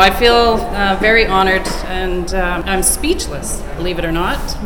Goat News attended Saturday night’s event and had the chance to speak with some of the evening’s winners.